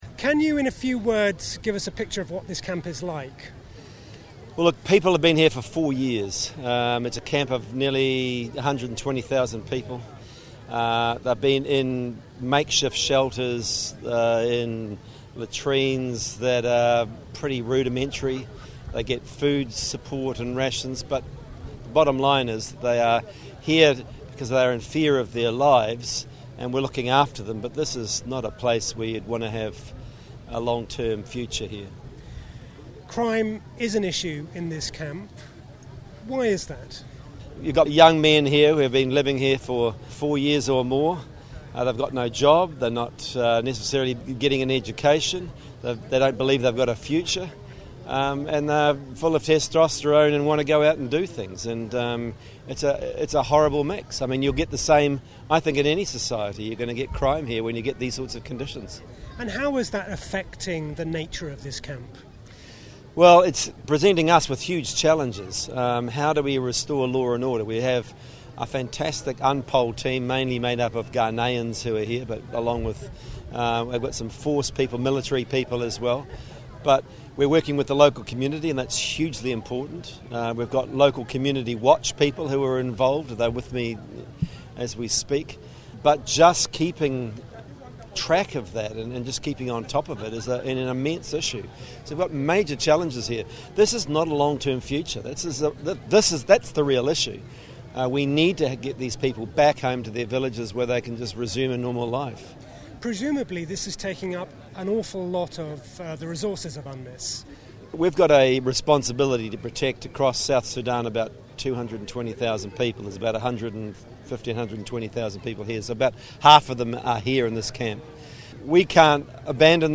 David Shearer, was speaking in Bentiu, north of South Sudan, where around 115,000 people are currently living in the Mission's largest Protection of Civilians site.
Radio Miraya